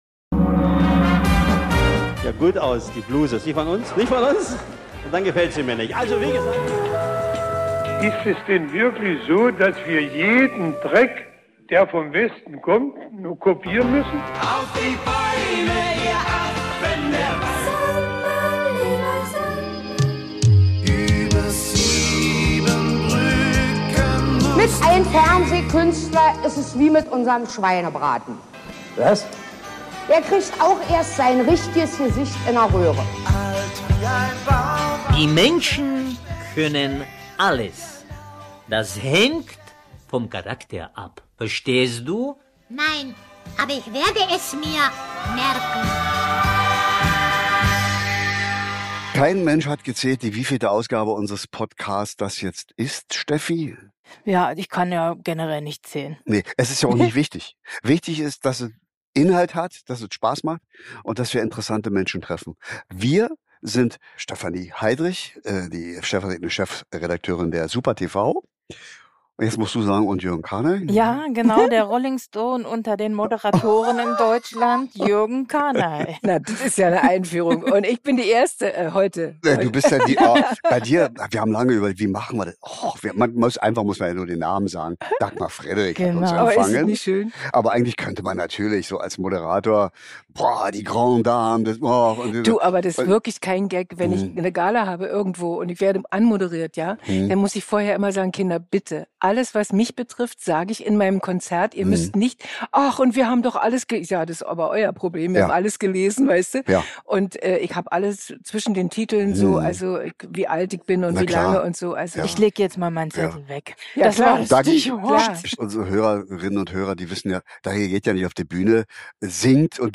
Bei einem Gläschen Sekt empfängt uns die Sängerin, Moderatorin und Grande Dame des DDR-Fernsehens bei sich zu Hause.
Dagmar Frederic ist grundzufrieden und das strahlt sie im Gespräch auch aus.